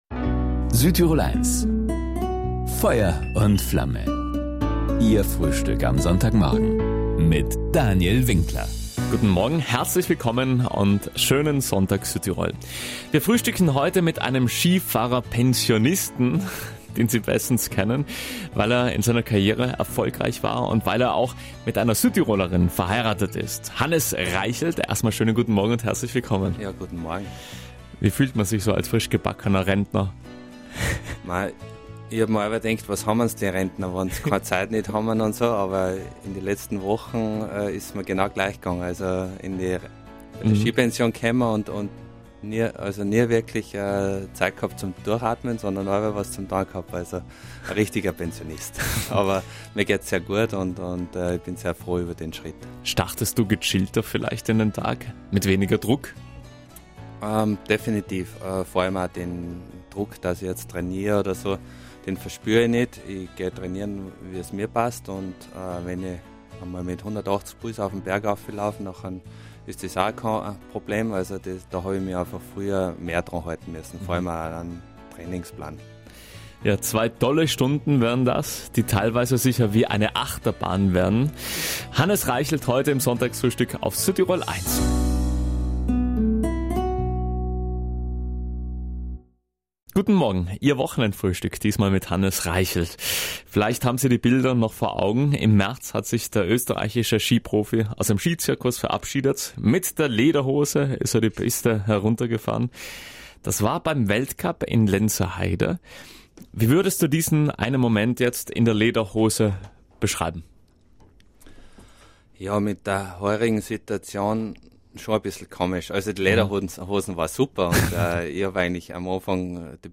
Ein Gespräch über viele Höhen und auch Tiefen im internationalen Skizirkus, erwartet Sie diesmal im Sonntagsfrühstück.
Ausführlich davon erzählte Hannes Reichelt am Sonntag in „Feuer und Flamme“ auf Südtirol 1... Hier zum Nachhören: